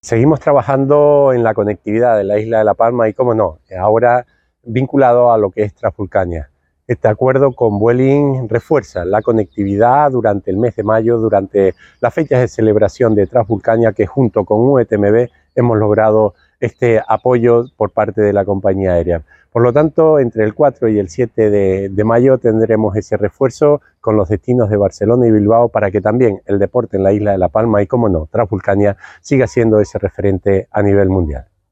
El consejero de Turismo, Raúl Camacho, destaca que “es de suma importancia contar con vuelos extras para todos aquellos corredores que participan en Transvulcania y viajan desde la Península y que nos ayudará a seguir posicionando esta prueba como referente a nivel mundial”.
Declaraciones audio Raúl Camacho Vuelin TRV.mp3